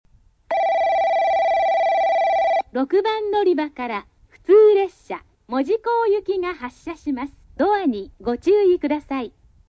スピーカー：UNI-PEX・SC-10JA（ソノコラム・ミニ型）
音質：C
６番のりば 発車放送 普通・門司港 (47KB/09秒)